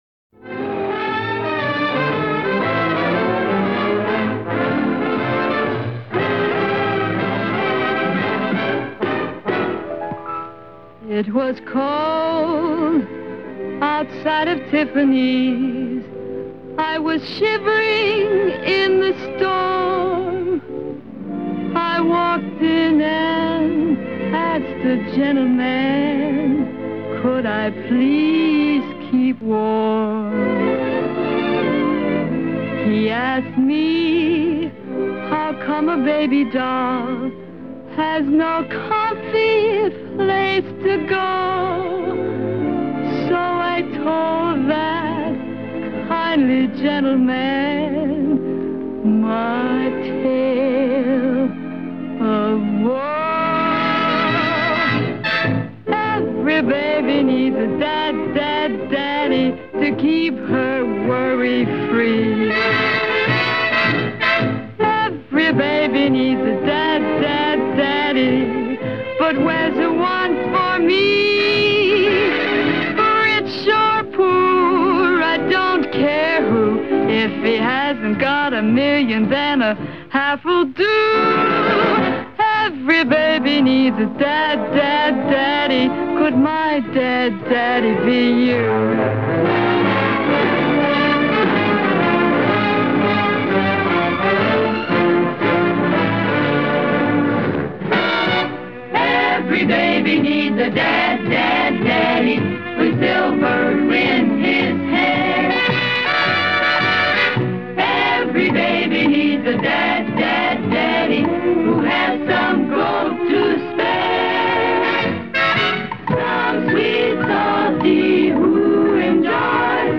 好萊塢性感偶像珍稀錄音